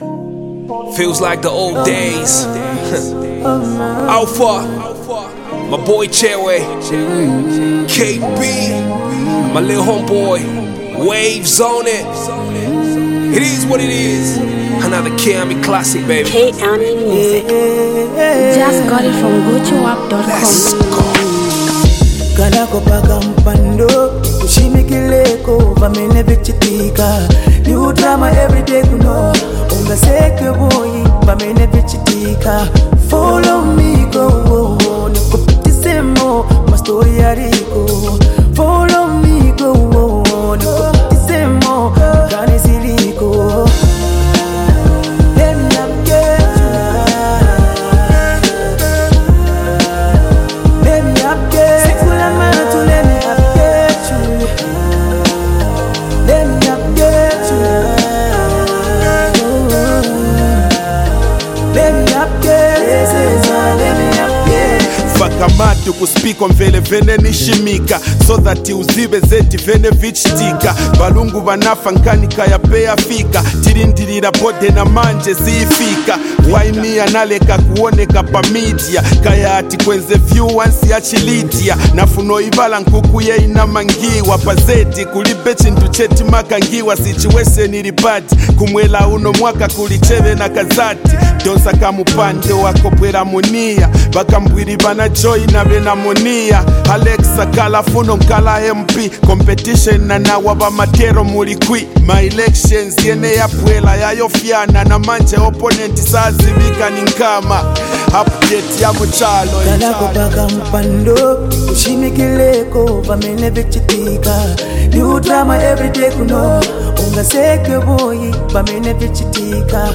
Zambian top notch rapper